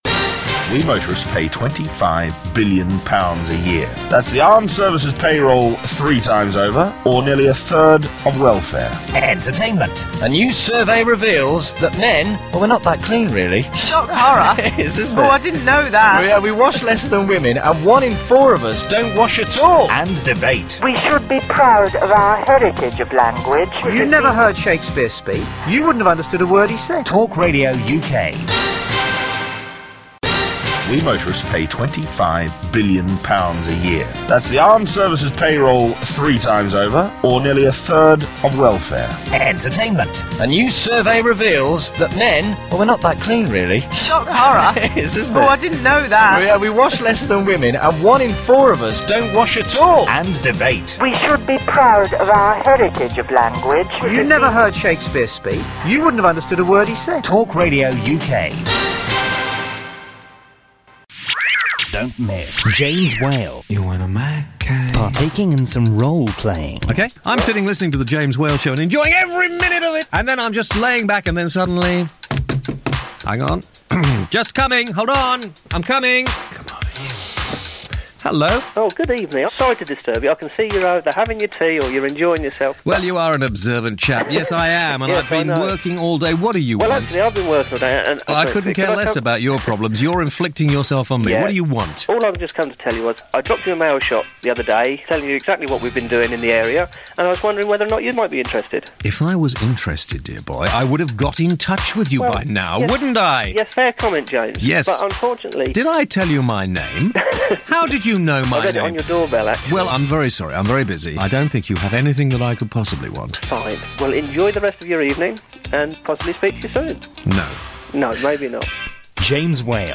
Talk Radio Montage